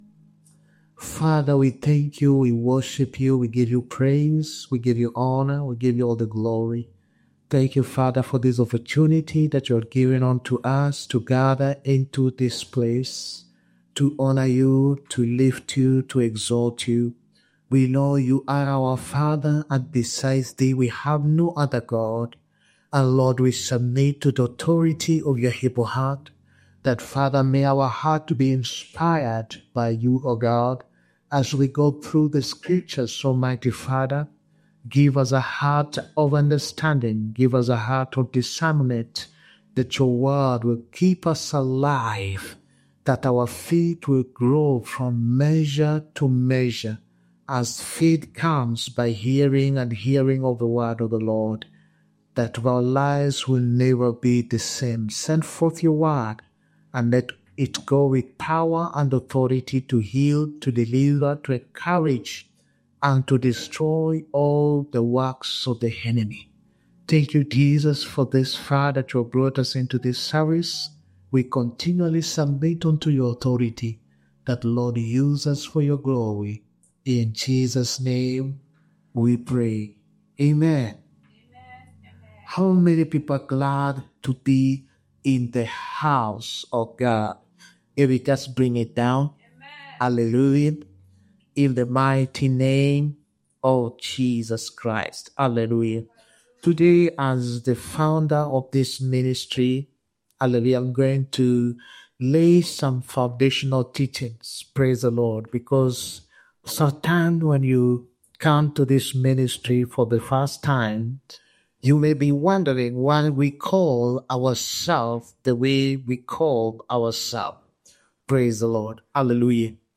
the Power of Restoration service